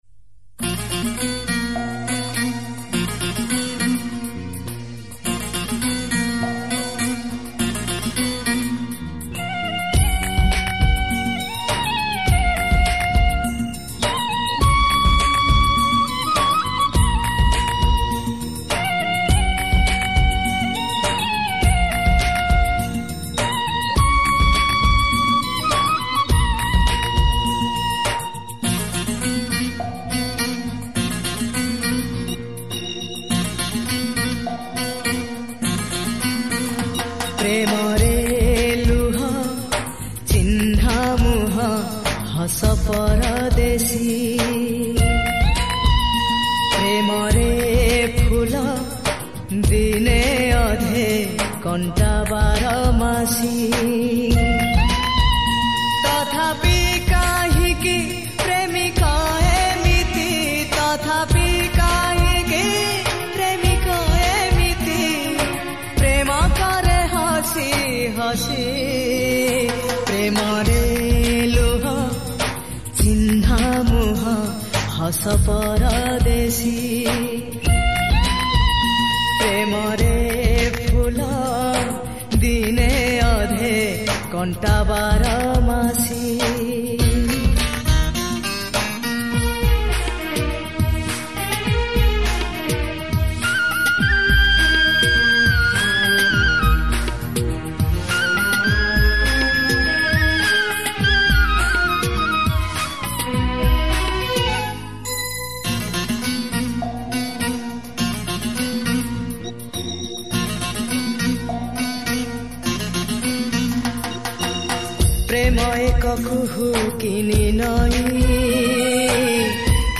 Romantic Song